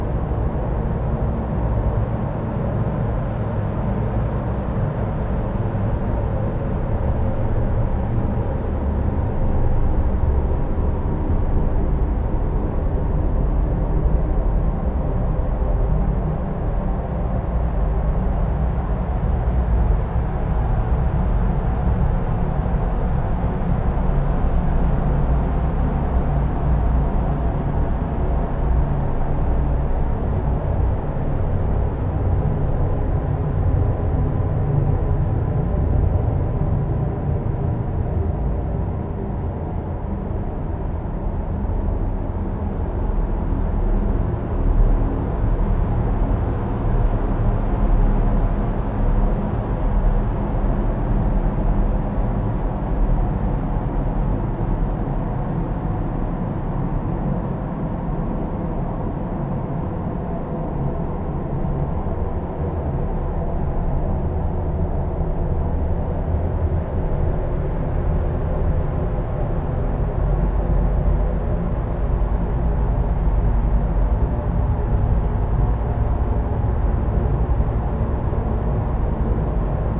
Cave_Loop_02.wav